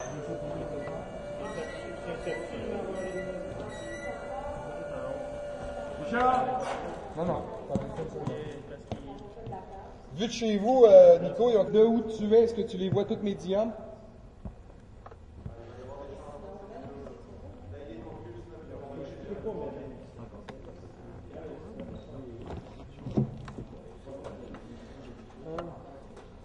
随机 " 剧院工作人员的舞台设置 魁北克语和普通话的声音2